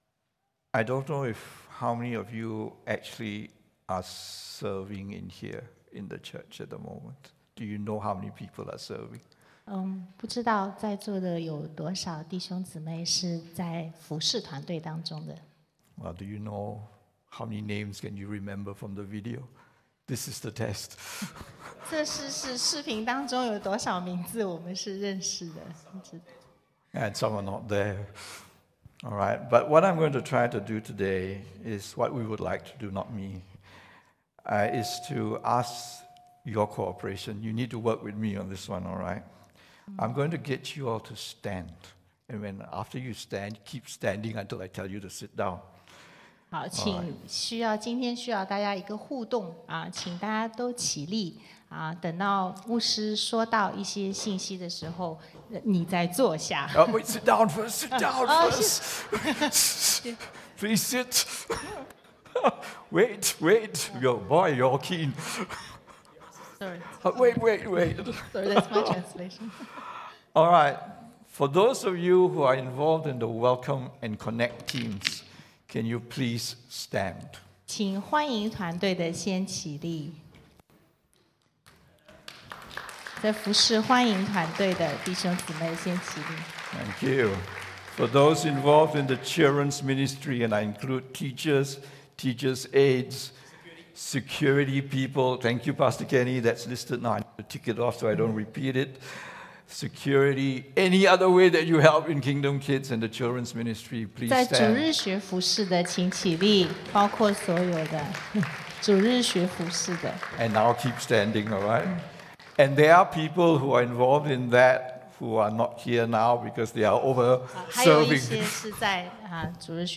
English Sermons | Casey Life International Church (CLIC)
A combined service from our English and Mandarin congregations to appreciate, as well as thank, our quiet heroes of the faith here at CLIC. To remind all of us that we have all been touched by the Master's hand and that we serve because our Lord Jesus came as The Servant King.